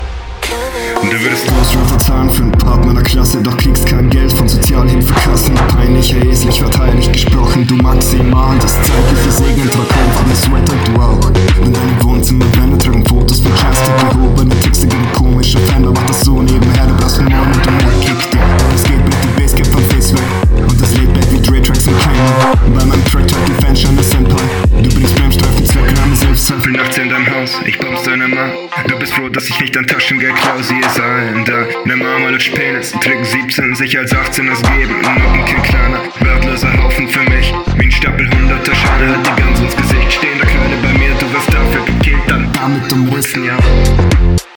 Aufnahmequalität ist jetzt besser.